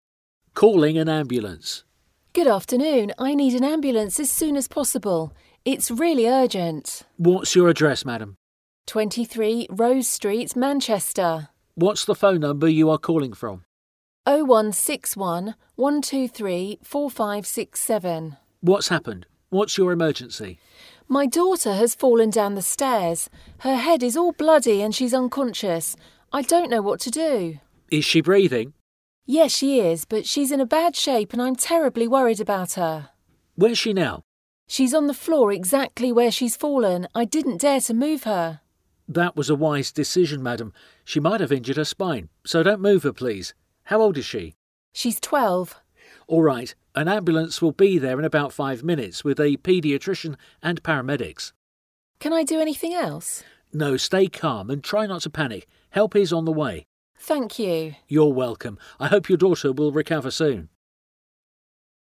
Ebből a párbeszédből megtanulhatod, hogyan hívj segítséget, ha baj van.
5_Dialogue_Ambulance.mp3